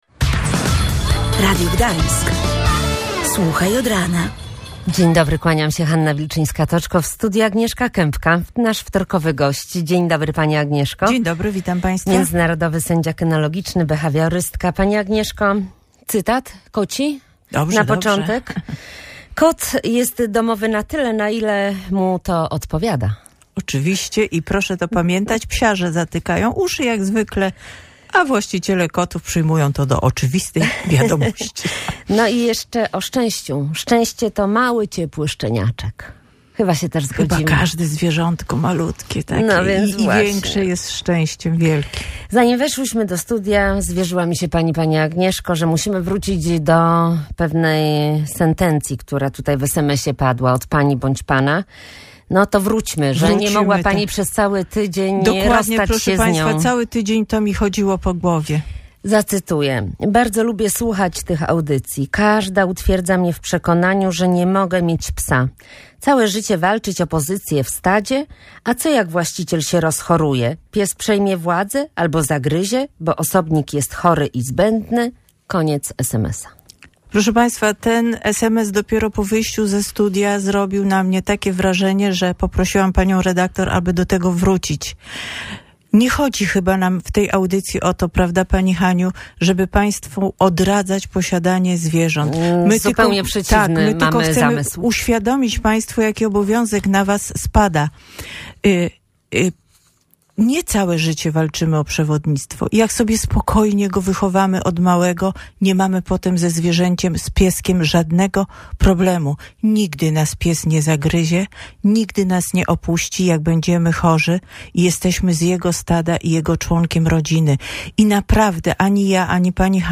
Jedna ze słuchaczek pytała, co zrobić jeśli jej 11-letnia suczka, choć zazwyczaj niezwykle grzeczna, głośno szczeka przy powitaniu i pożegnaniu właścicieli.